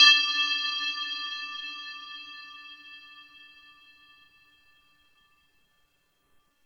drum-hitfinish2.wav